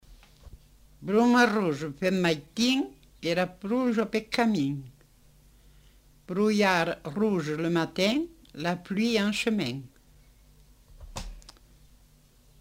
Proverbe météorologique
Aire culturelle : Comminges
Lieu : Montauban-de-Luchon
Effectif : 1
Type de voix : voix de femme
Production du son : récité
Classification : proverbe-dicton